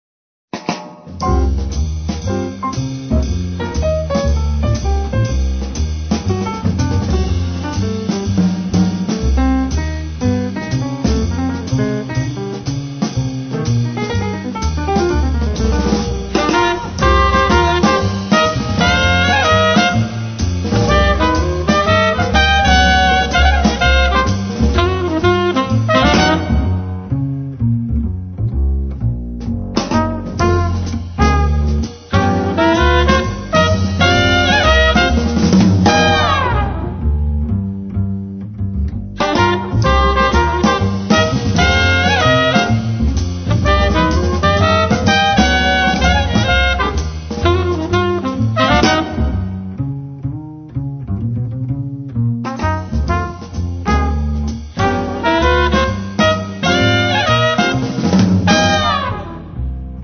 Sassofono tenore e soprano
tromba e flicorno
Pianoforte
Contrabbasso
Batteria